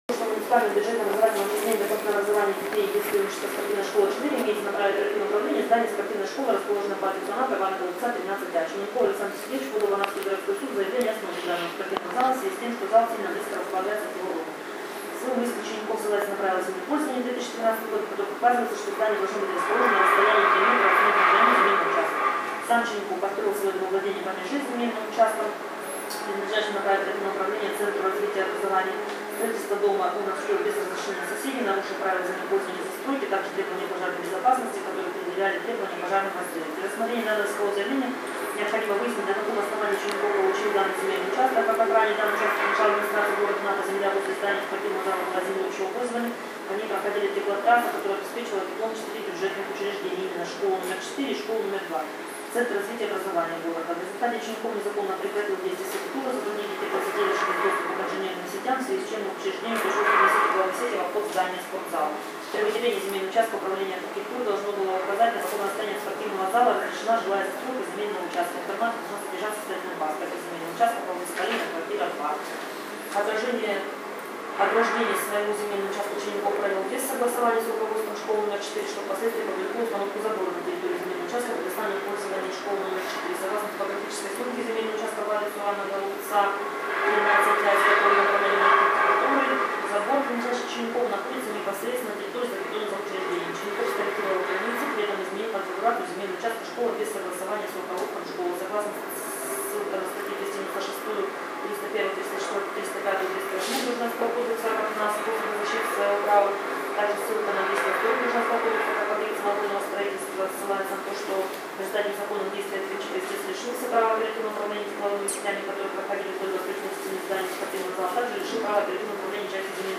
Аудиозапись судебного процесса